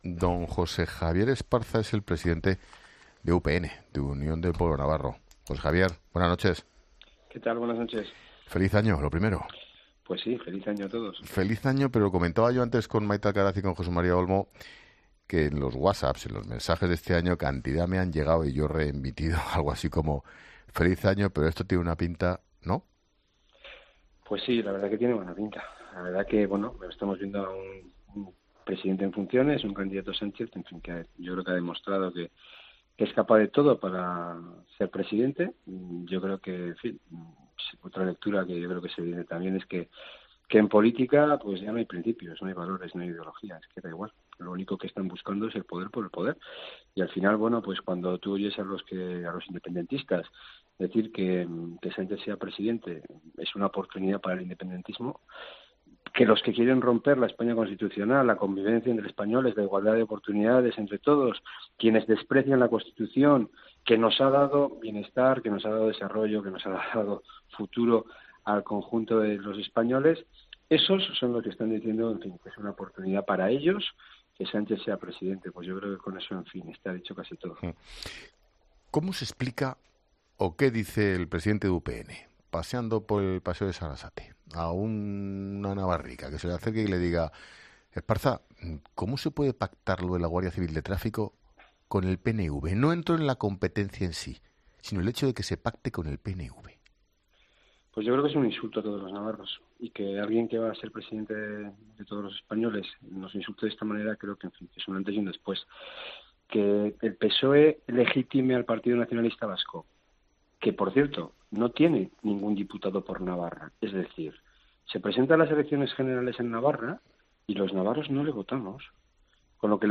Así lo declaraba Esparza en los micrófonos de La Linterna, junto a Ángel Expósito.